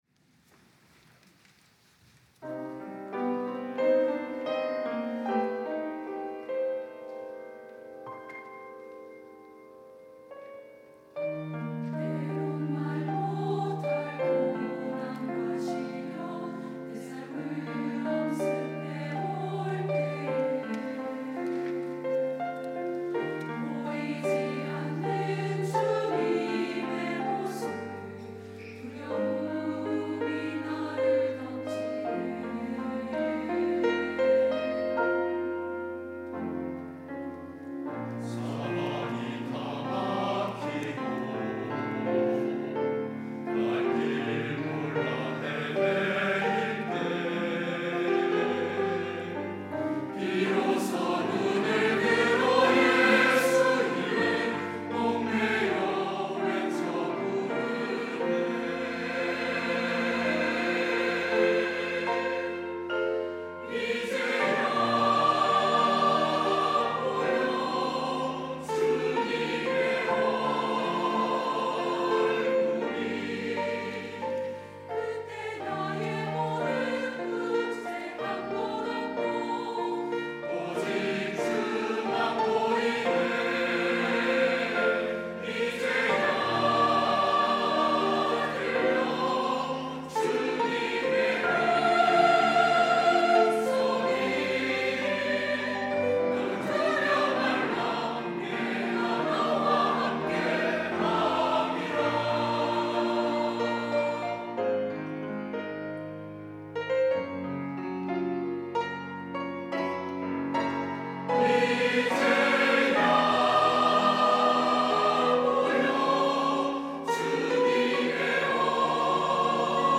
할렐루야(주일2부) - 이제야 보이네
찬양대